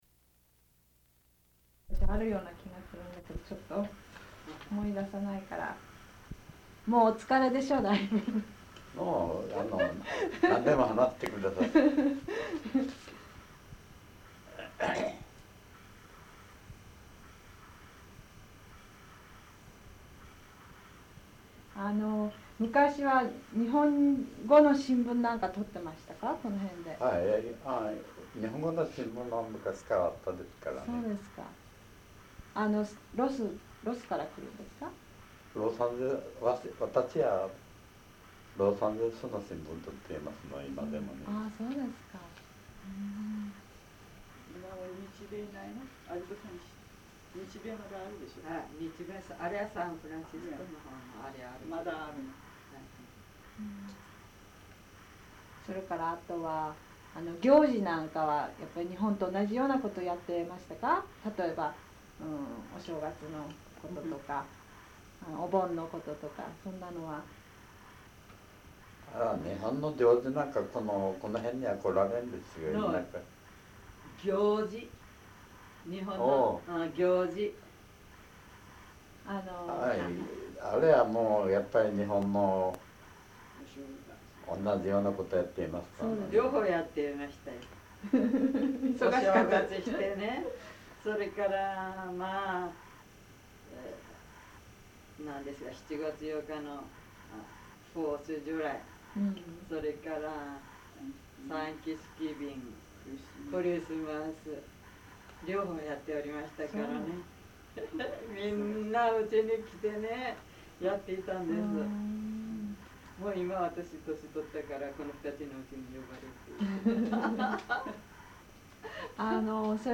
Sides A and B of the audiocassette
Ethnic Studies Oral History Collection